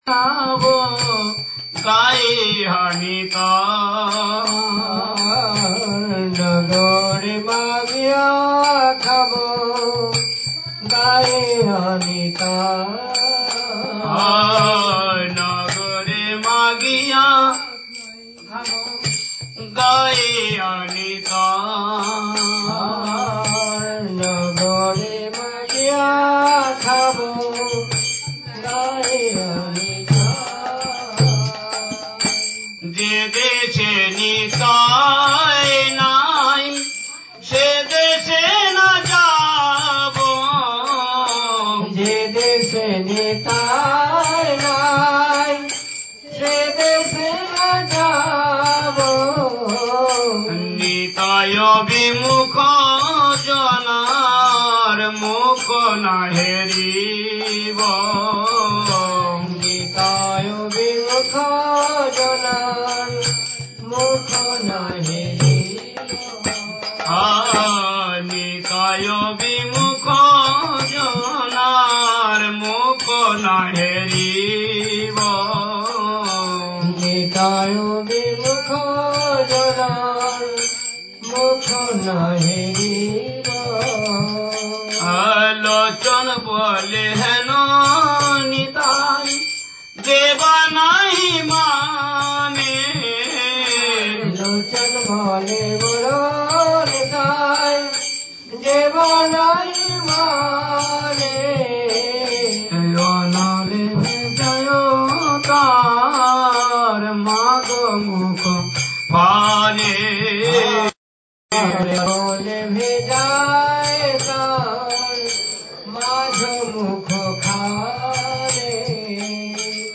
Audio record of the festival: